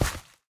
minecraft / sounds / step / gravel4.ogg
gravel4.ogg